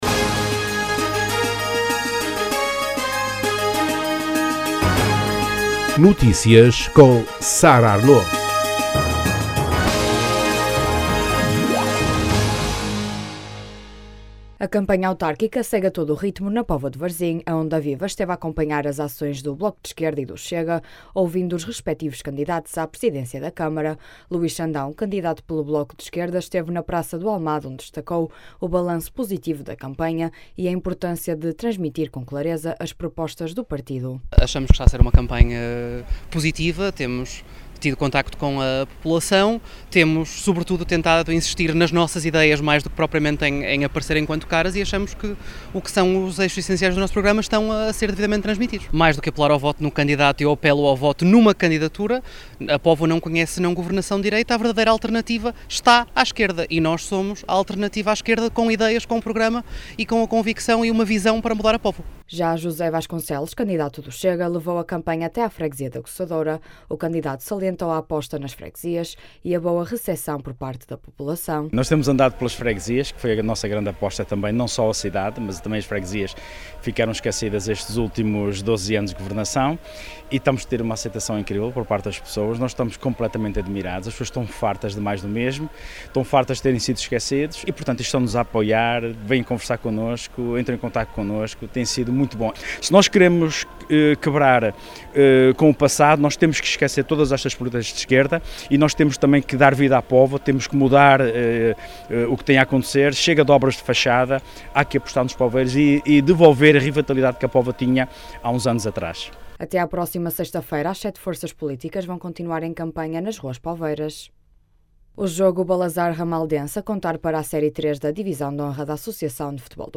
Bloco de Esquerda e Chega em contacto com a população poveira Detalhes Categoria: Notícias Regionais Publicado em terça-feira, 07 outubro 2025 09:23 Escrito por: Redação A campanha autárquica segue a todo o ritmo na Póvoa de Varzim. A Onda Viva esteve a acompanhar as ações do Bloco de Esquerda e do Chega, ouvindo os respetivos candidatos à presidência da Câmara.